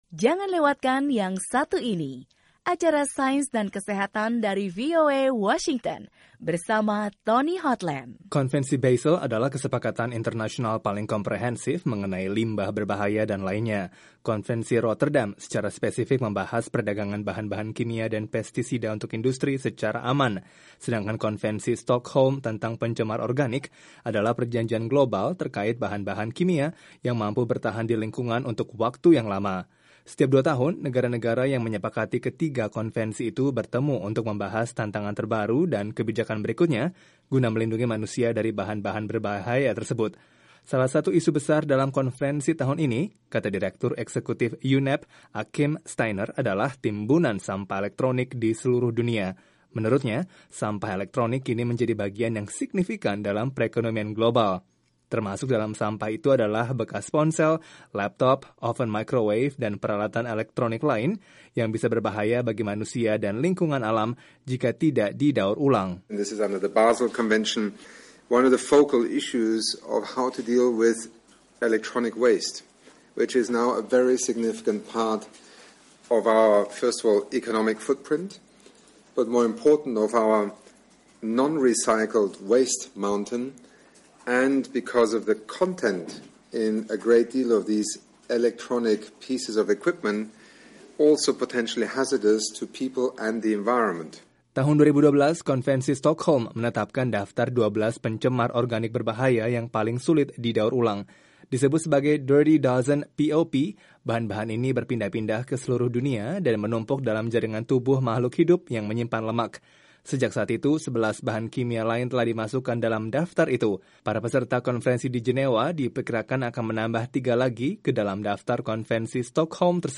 Sekitar 1.500 peserta dari 180 negara mengikuti konferensi pengelolaan limbah kimia berbahaya agar tidak membahayakan jiwa. Dalam pertemuan di Jenewa itu, mereka juga berupaya memperkuat tiga konvensi internasional. Laporan